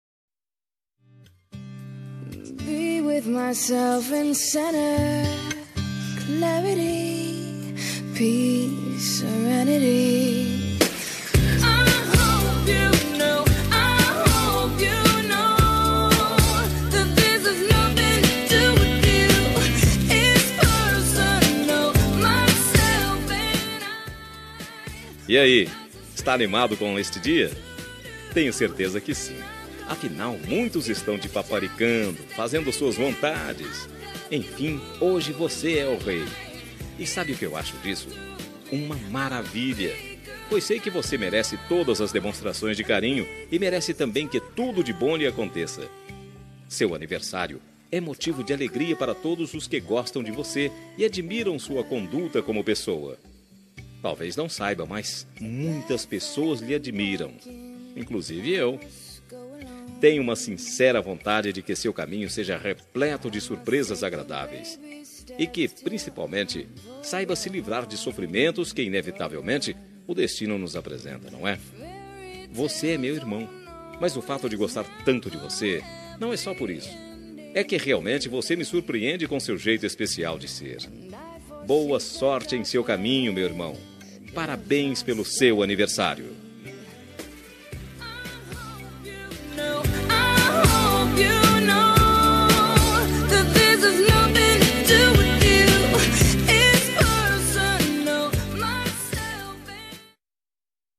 Telemensagem de Aniversário de Irmão – Voz Masculina – Cód: 4032